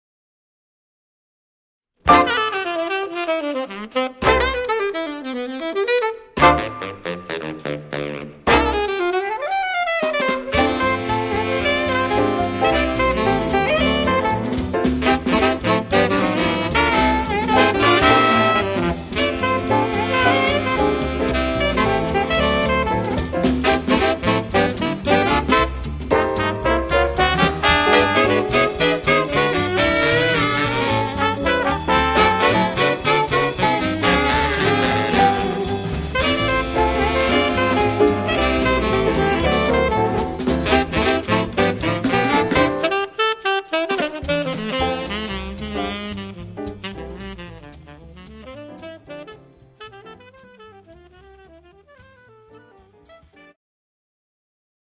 The Best In British Jazz
Recorded at The Sound Cafe, Midlothian